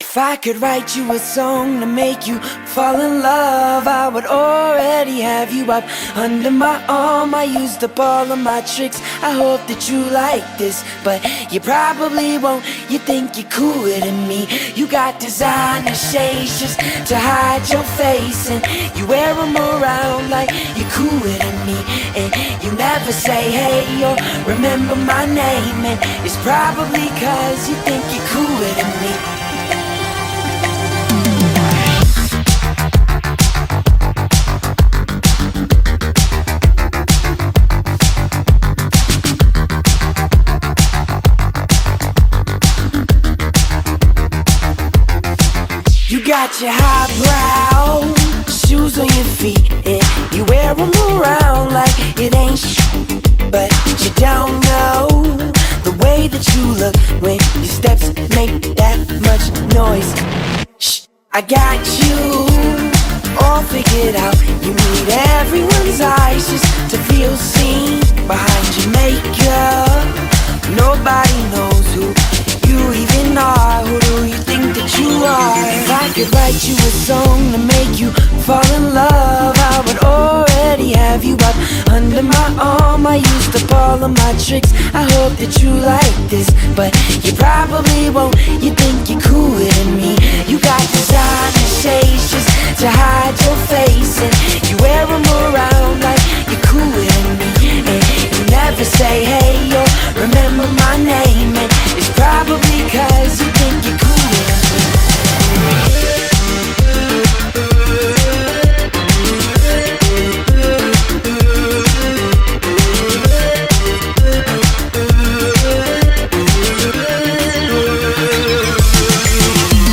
BPM130
Audio QualityLine Out